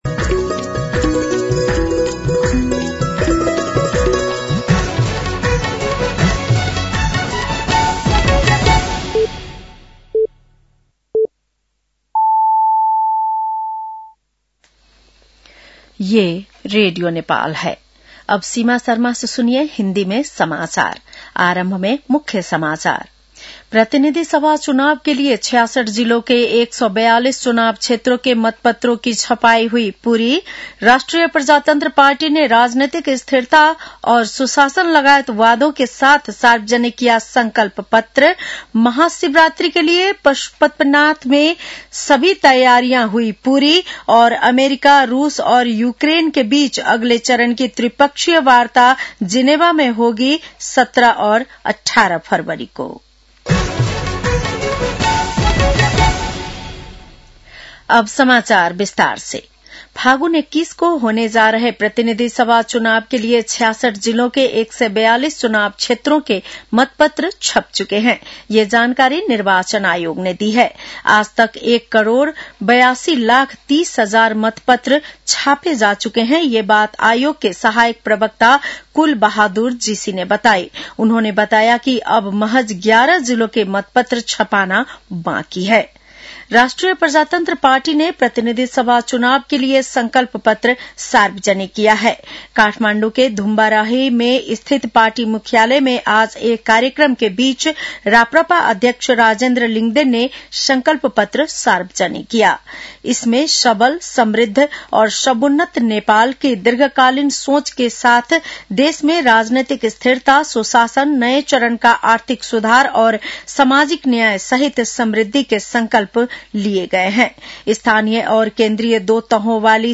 बेलुकी १० बजेको हिन्दी समाचार : २ फागुन , २०८२
10-pm-news-1-2.mp3